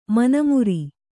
♪ mana muri